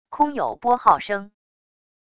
空有拨号声wav音频